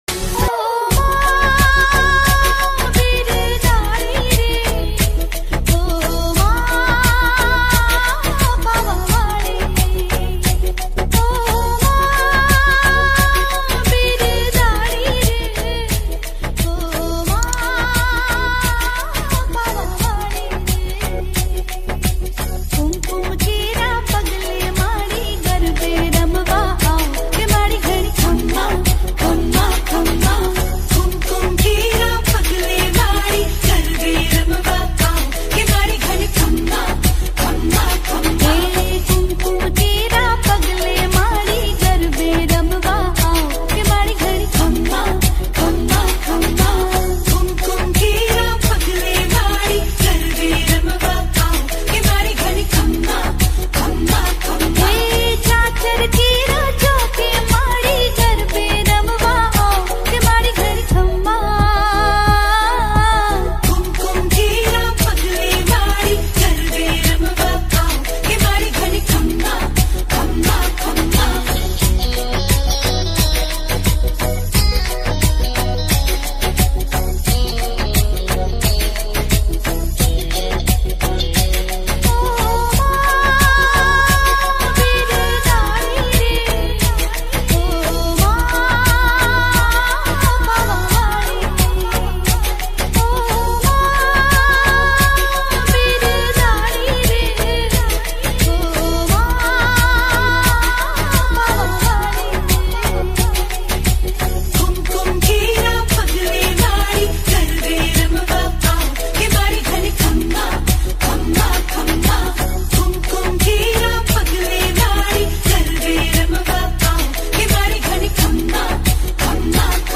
ગીત સંગીત ગરબા - Garba